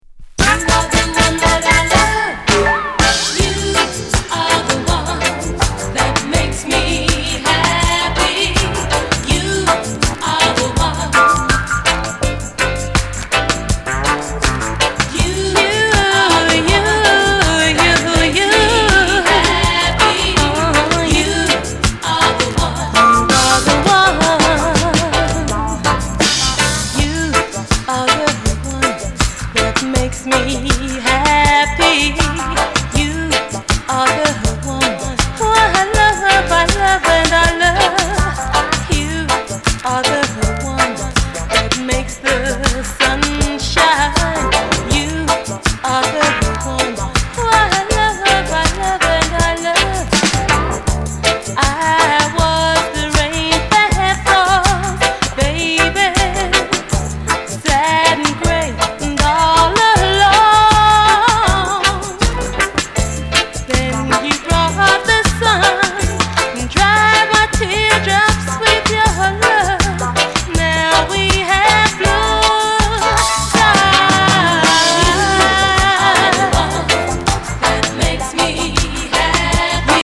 Genre: Reggae/ Lovers Rock